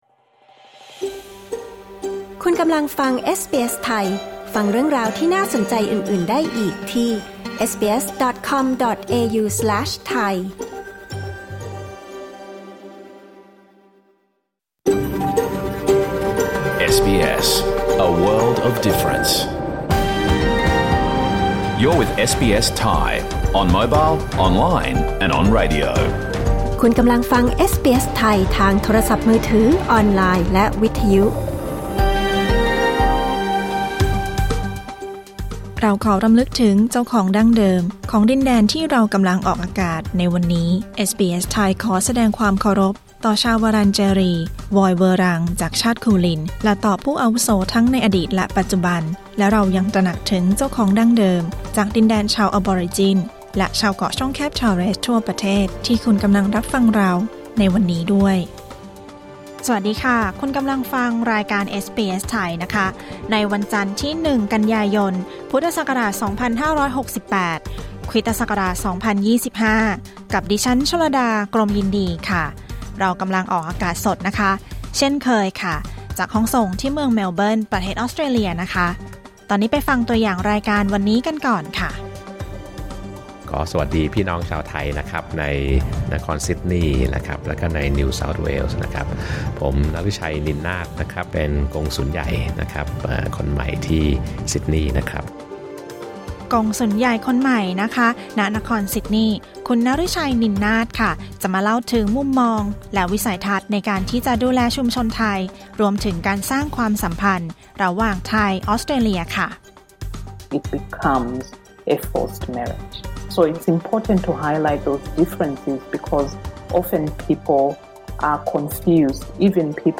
รายการสด 1 กันยายน 2568